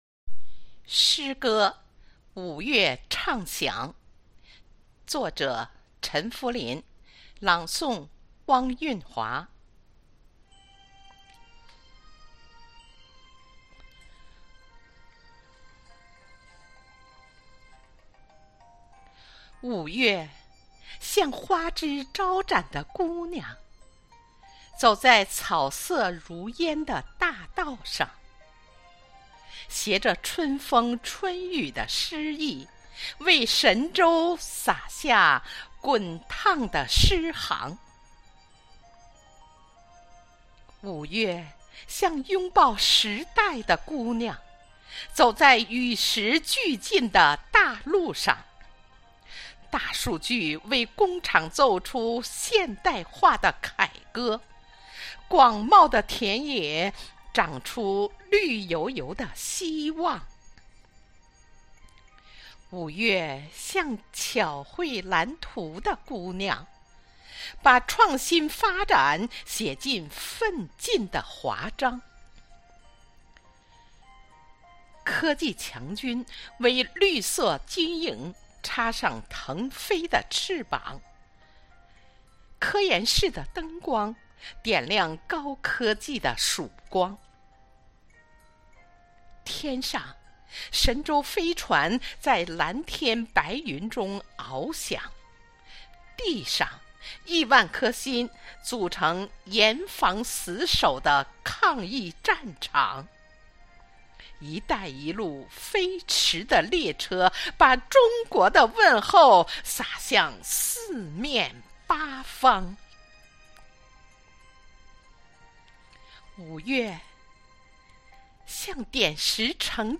云朗诵会